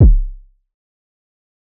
IN DA CLUB KICK.wav